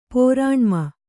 ♪ pōrāṇma